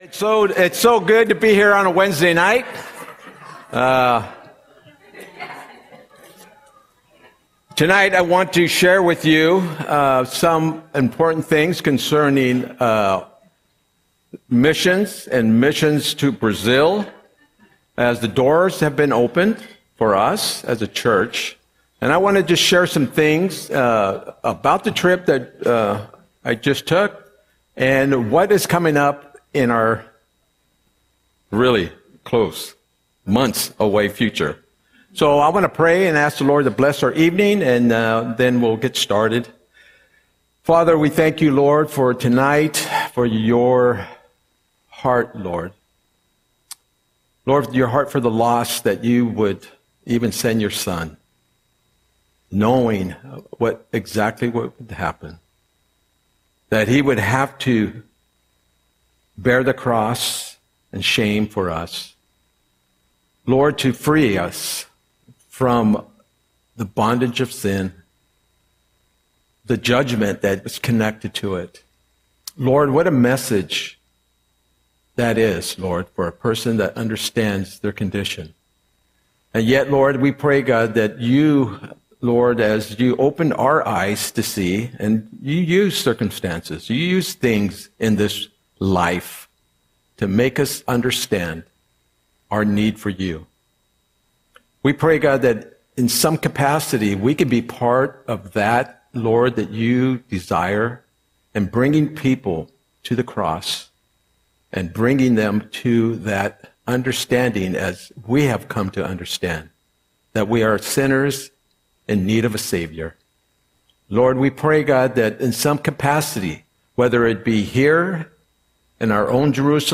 Audion Sermon - May 28, 2025